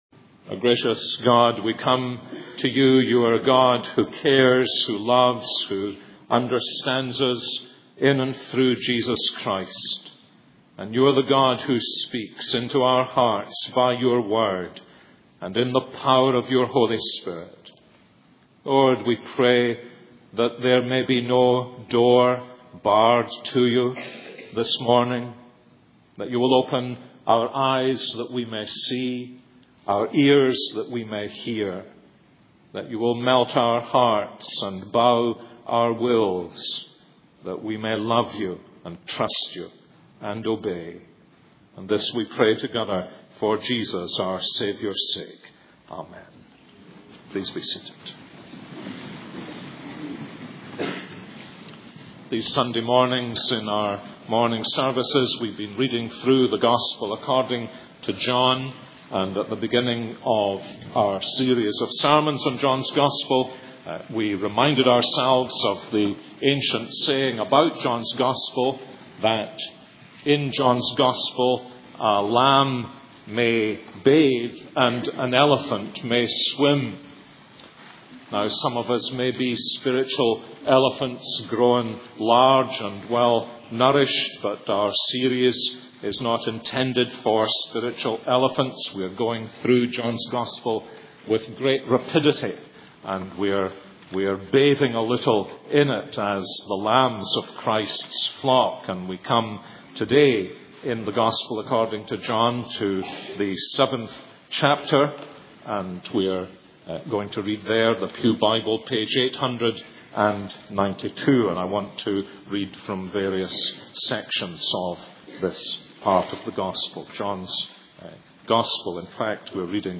The following sermons are in MP3 format.